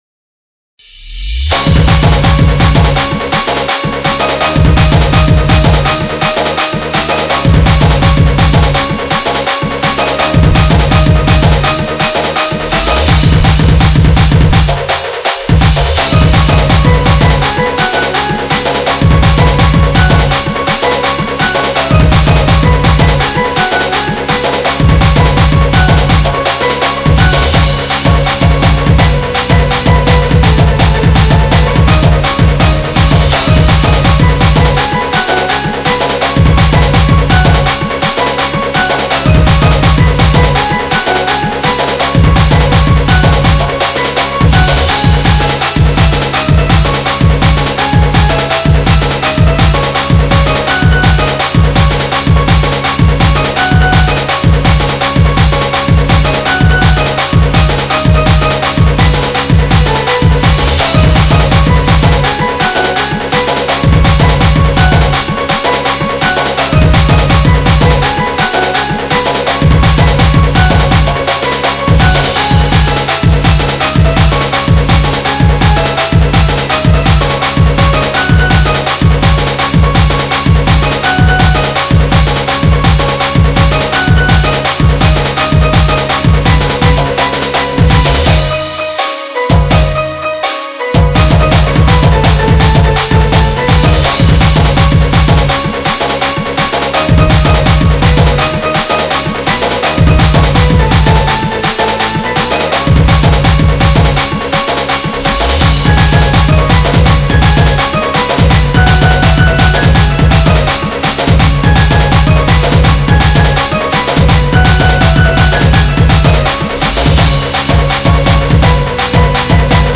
Rela Gadi Chhuku Chhuku Sambalpuri Instrumental
SAMBALPURI INSTRUMENT DJ REMIX